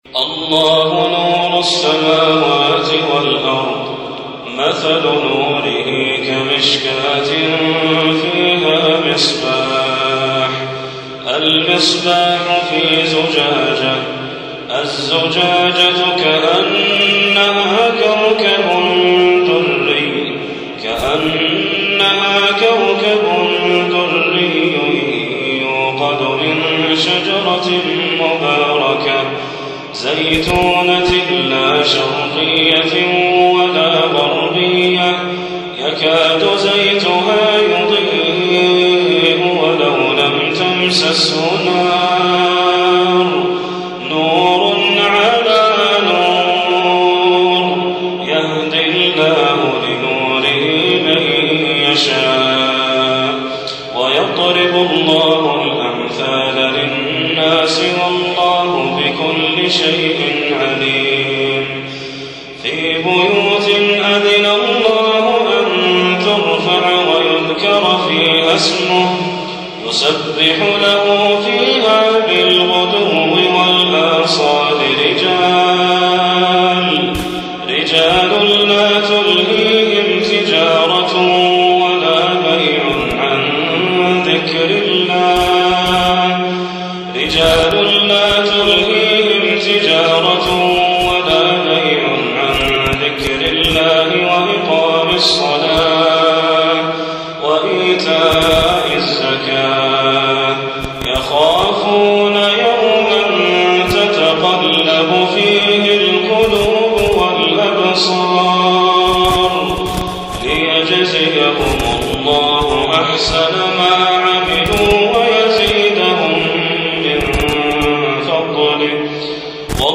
تسجيل من صلاة القيام =